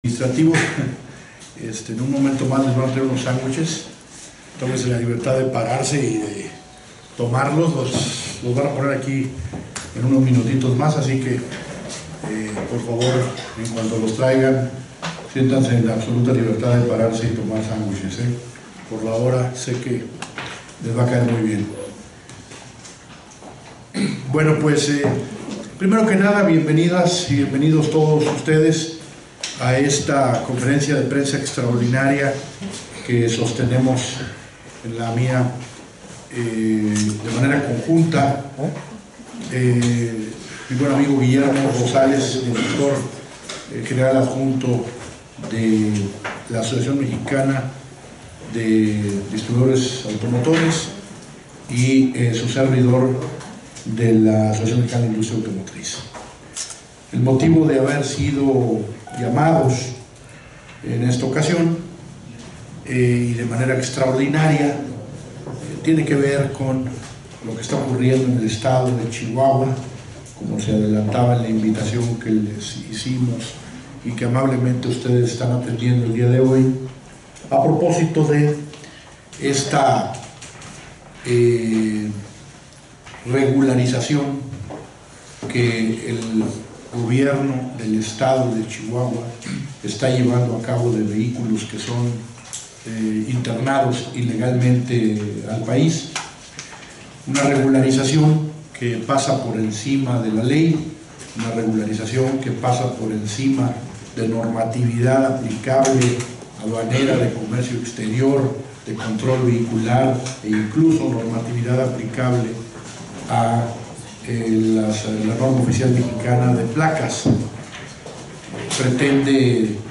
Descarga Boletín AMDA-AMIA 160318 aquí Descarga foto en ALTA resolución aquí Descarga foto en baja resolución aquí Descarga audio de la conferencia aquí